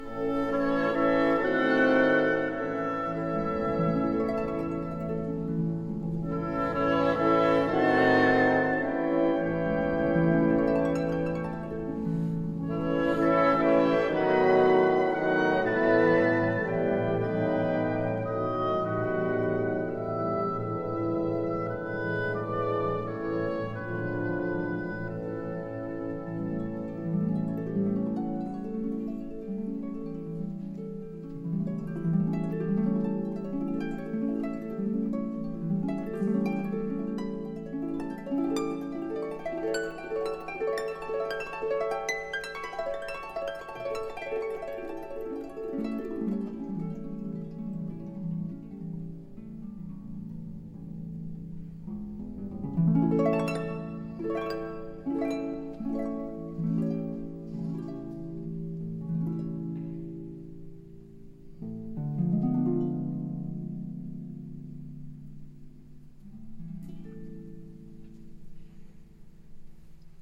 ÀUDIOS TALLER D'ARPA - Audicions Escolars